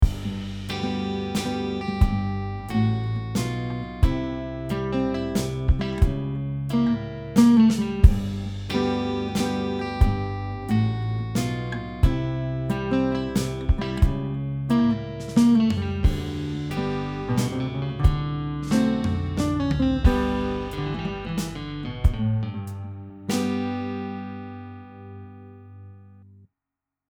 Fingerstyle Country Waltz